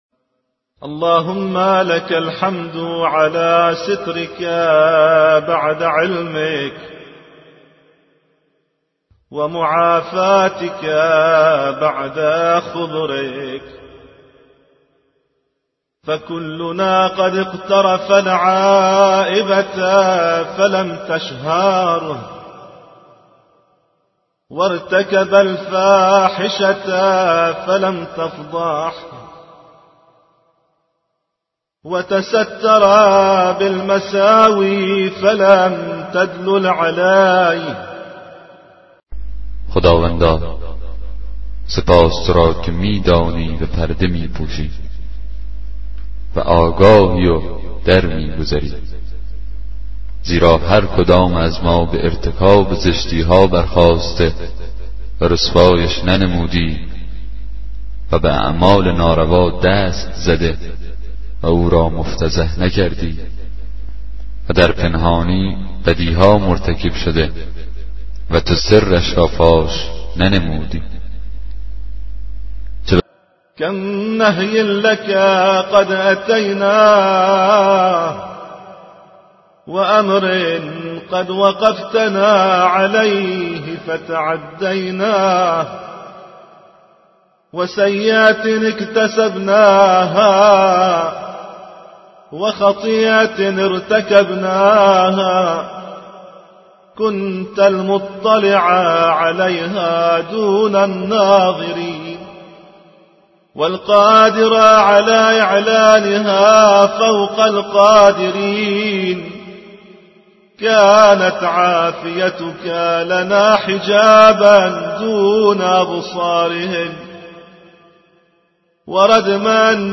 کتاب صوتی دعای 34 صحیفه سجادیه